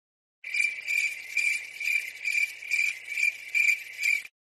Awkward Cricket Bouton sonore
Animal Sounds Soundboard1,266 views